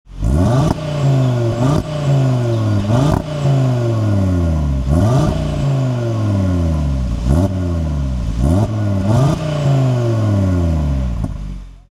Listen To The 5-Cylinder Symphony!
• RS Sports Exhaust System with Black Oval Tips
• 2.5TFSI 5-Cylinder Turbo Engine (400PS & 500NM)
VEO-ascari-blue-rs3-revs.mp3